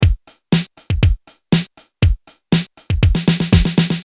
EDM-drum-loop-driving-beat.mp3